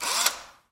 工具 " DN KP 手钻02
描述：手钻机录制的不同定位的普通WMA/MP3/录音机LAZER
标签： 机械 手电钻 工具 声音效果
声道立体声